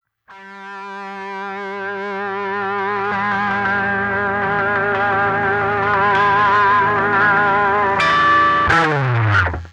Track 02 - Guitar Lick 08.wav